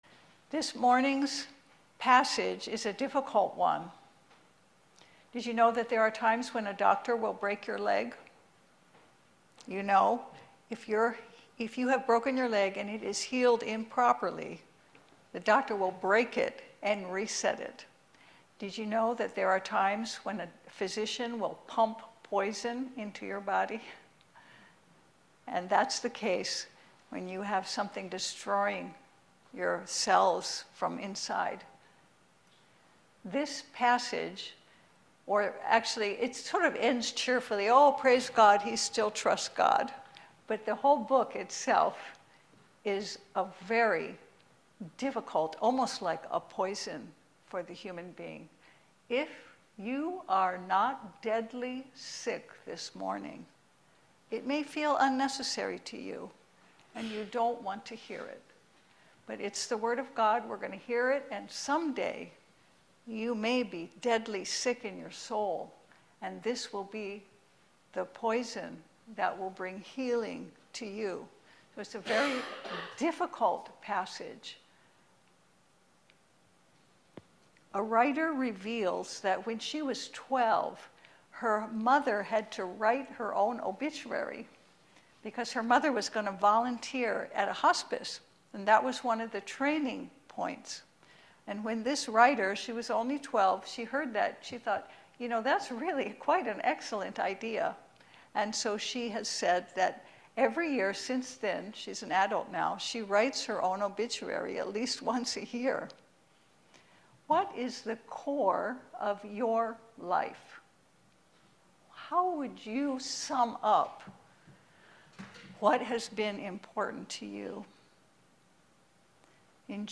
2:1-10 Service Type: Sunday Service No one can prove themselves pure in God’s eyes or count on a good outcome due to righteous behavior.